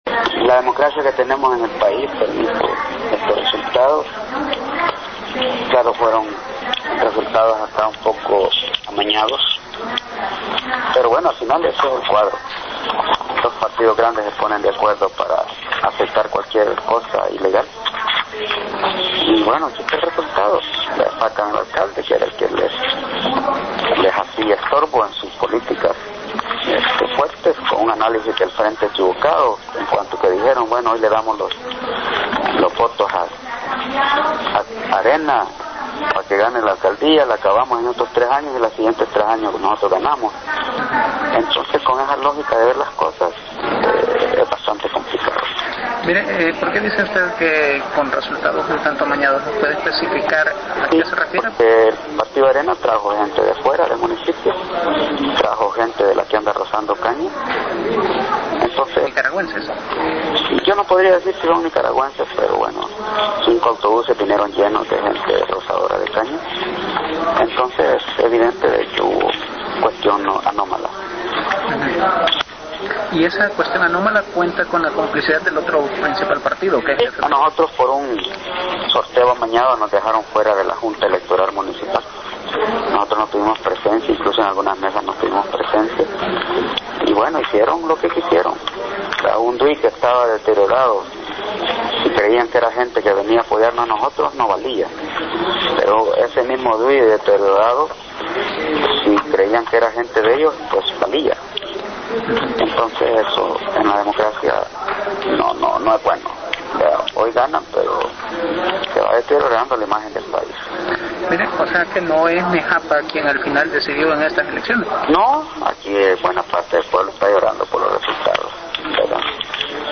Todavía se le escucha hablar con tristeza. Esta es su explicación de la derrota electoral.